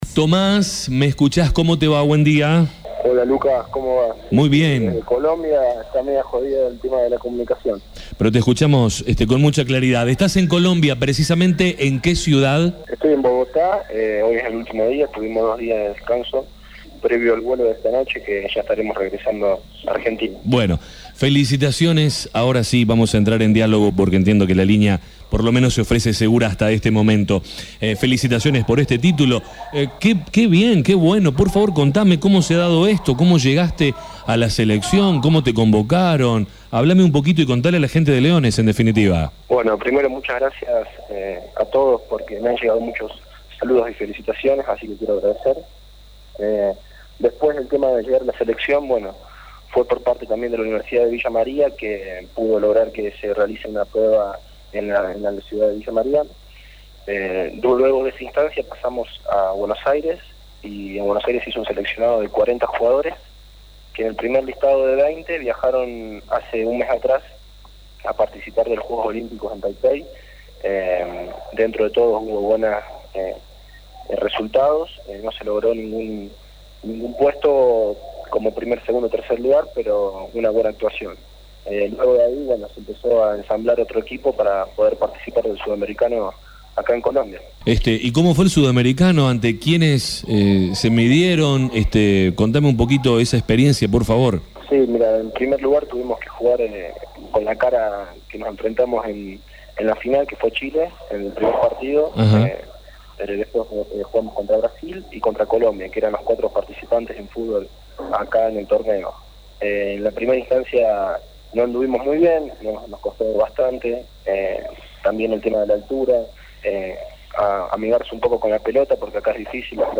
Escuchá un extracto de la nota.